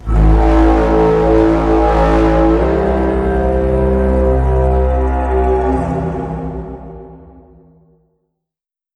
tripod horn.wav